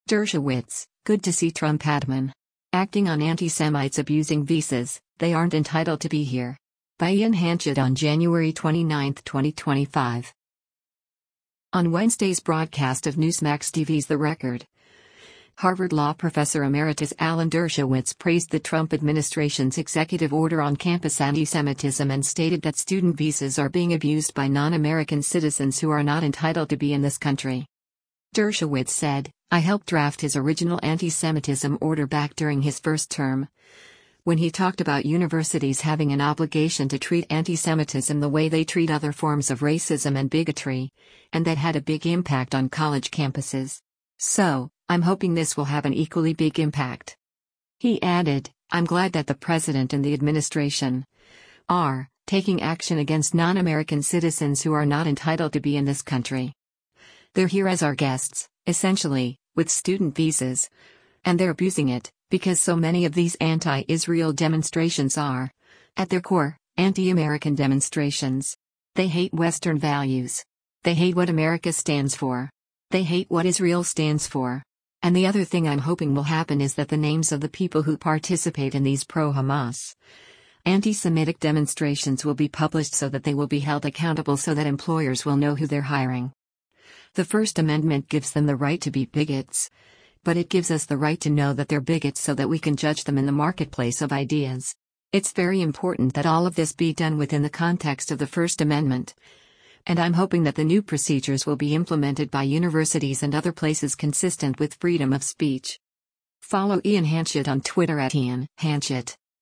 On Wednesday’s broadcast of Newsmax TV’s “The Record,” Harvard Law Professor Emeritus Alan Dershowitz praised the Trump administration’s executive order on campus antisemitism and stated that student visas are being abused by “non-American citizens who are not entitled to be in this country.”